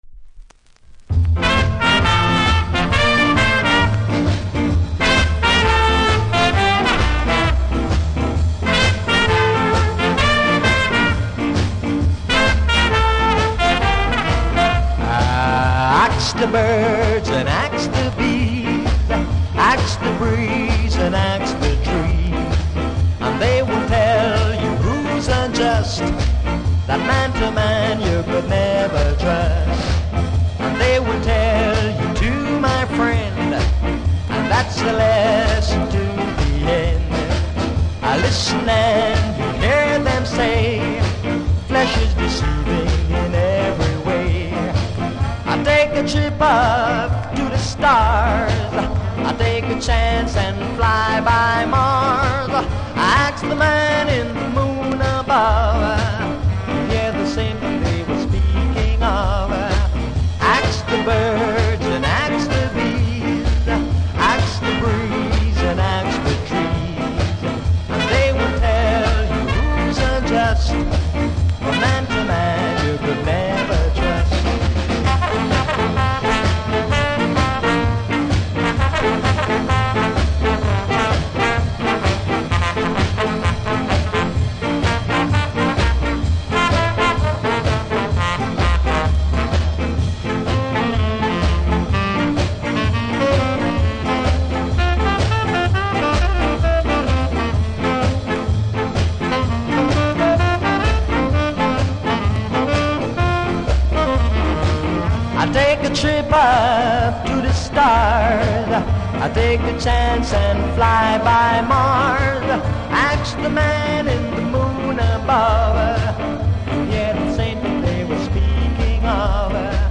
プレス起因の凹が数か所ありノイズ感じます。